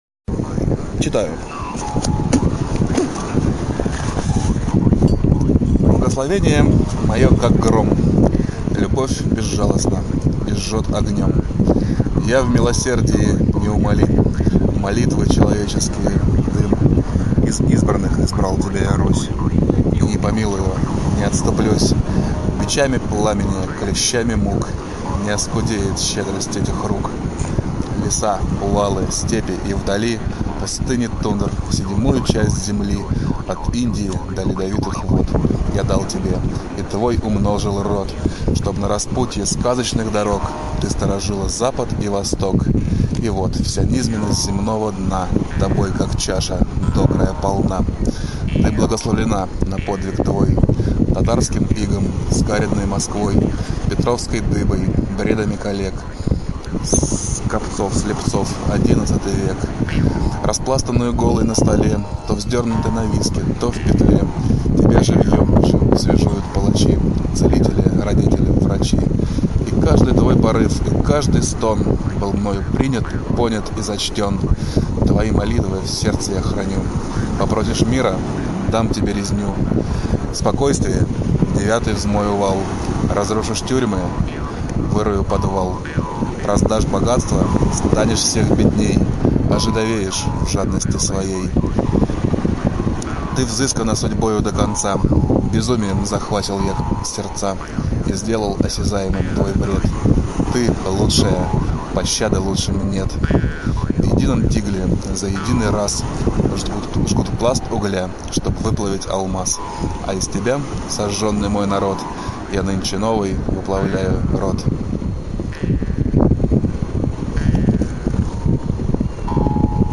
Blagoslovenie-M.Voloshin-veter-vargan-i-priboj-stih-club-ru.mp3